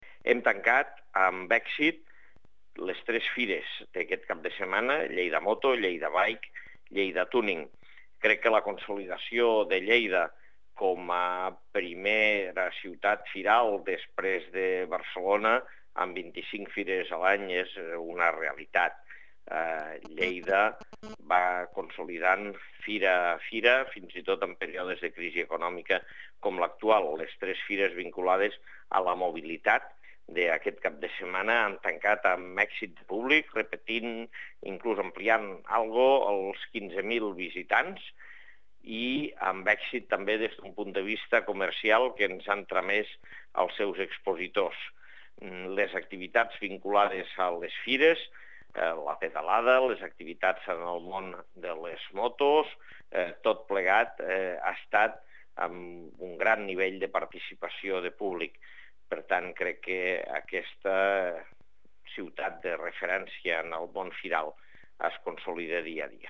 Fitxers relacionats Tall de veu de l'alcalde, Àngel Ros, sobre la valoració de LleidaMoto, LleidaBike i Lleida Tuning (1.0 MB) Fotografia 1 amb major resolució (2.4 MB) Fotografia 2 amb major resolució (1.9 MB)
tall-de-veu-de-lalcalde-angel-ros-sobre-la-valoracio-de-lleidamoto-lleidabike-i-lleida-tuning